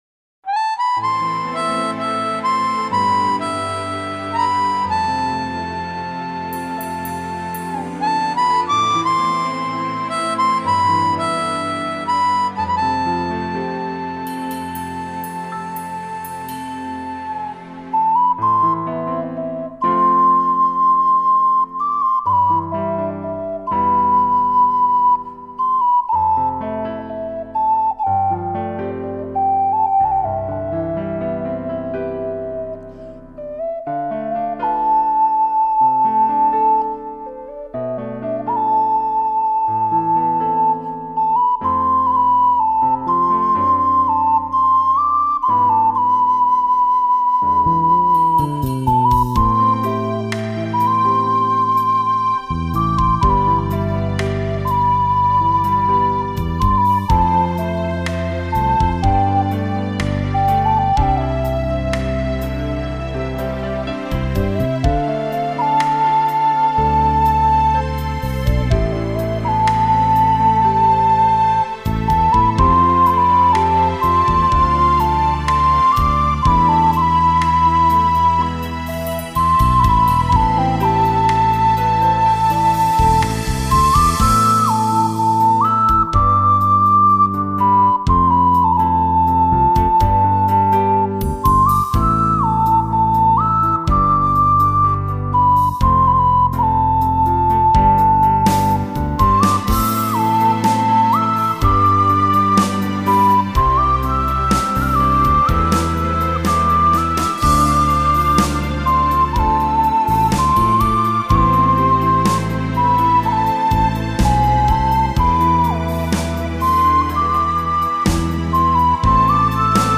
（思念的季节/陶笛） 激动社区，陪你一起慢慢变老！